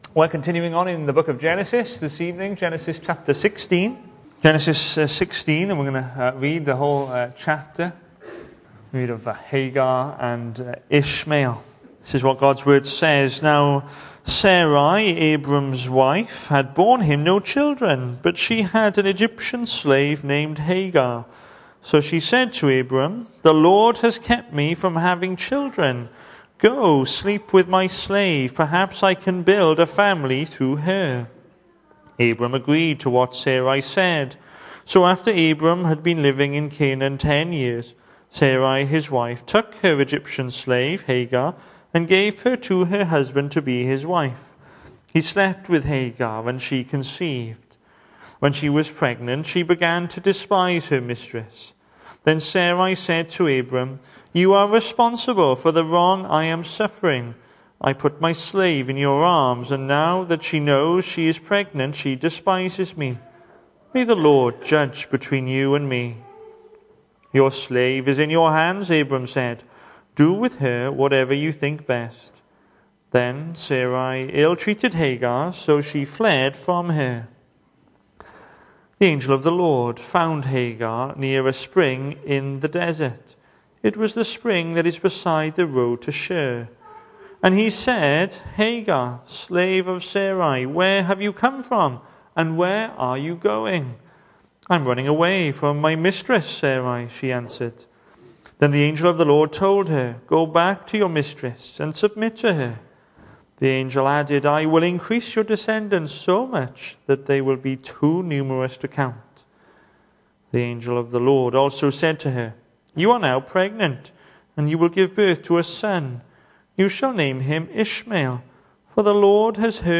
Hello and welcome to Bethel Evangelical Church in Gorseinon and thank you for checking out this weeks sermon recordings.
The 19th of October saw us hold our evening service from the building, with a livestream available via Facebook.